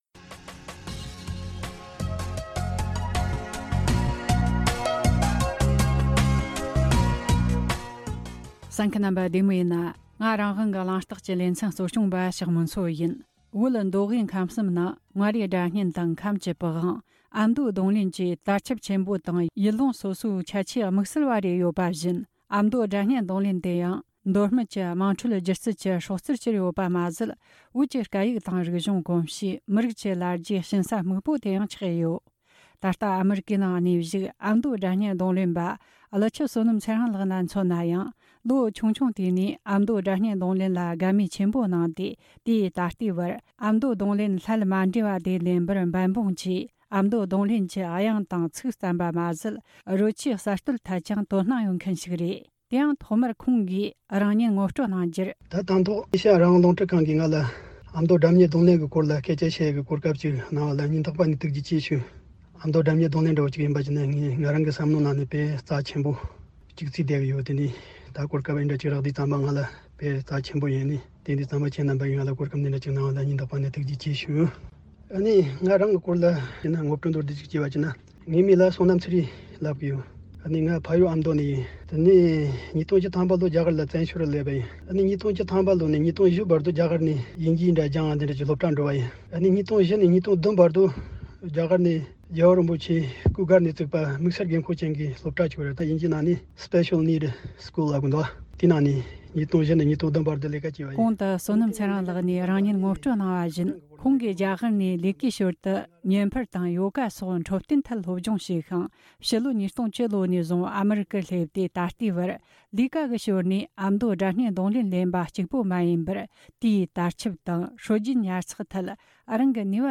སྒྲ་སྙན་རྡུང་ལེན་པ
ཕྱོགས་བསྡུས་ཞུས་པའི་ལས་རིམ།